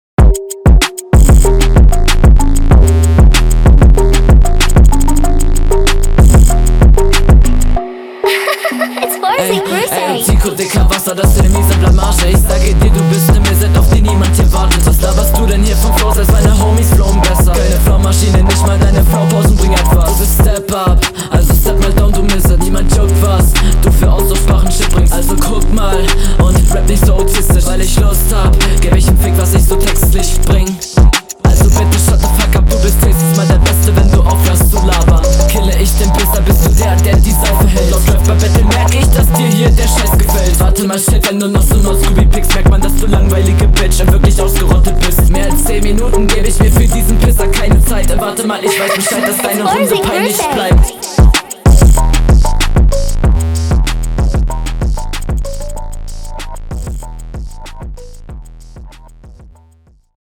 "Ey ey" im Intro klingt stimmlich sehr hoch (vor allem das erste ey) haha aber …
Flow: Flowtechnisch find ich das ganze schon sehr gut, auch die kleinen Variationen wirken schon …